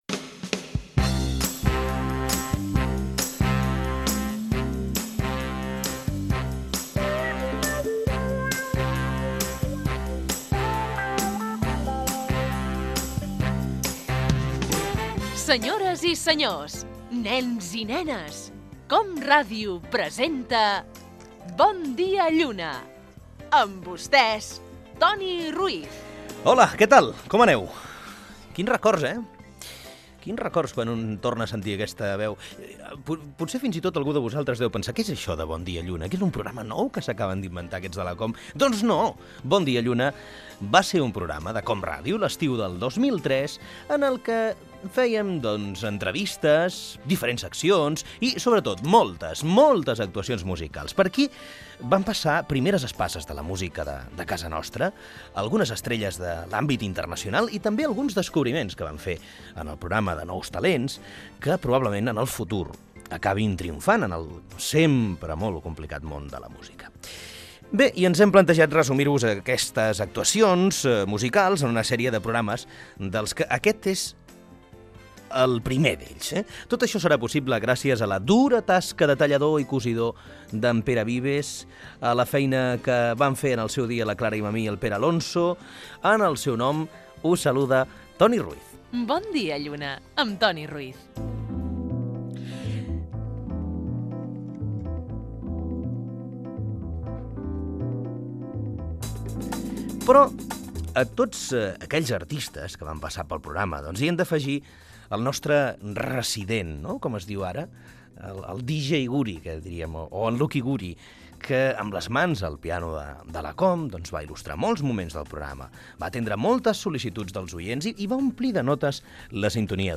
Record i resum del programa emès l'estiu de 2003. Careta del programa, presentació, indicatiu i tema musical interpretat per Lucky Guri al piano de l'estudi de COM Ràdio
Entreteniment